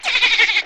Plants vs. Zombies sounds (звуки из игры) Часть 1
dolphin_before_jumping.mp3